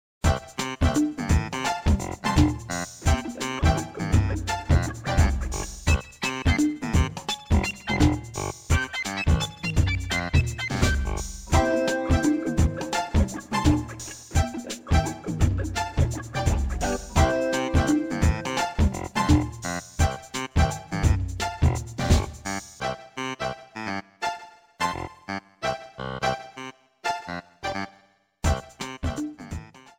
4=Cut off and fade-out }} Category